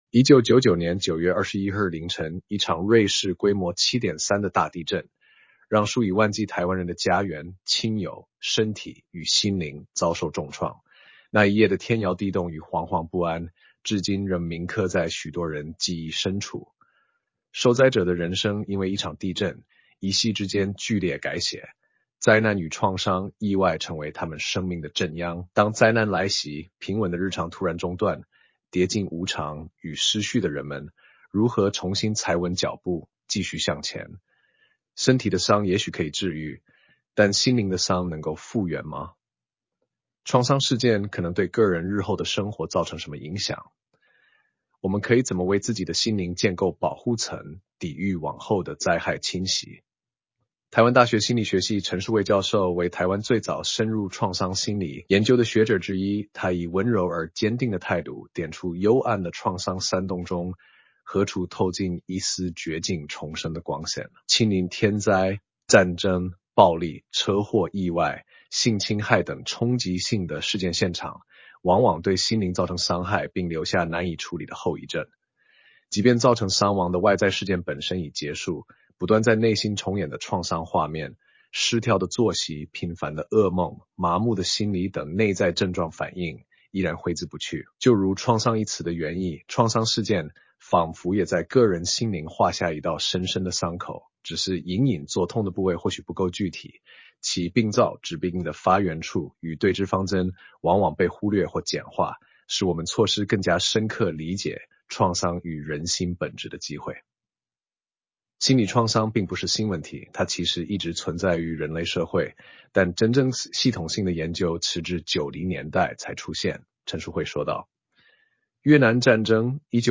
全文朗讀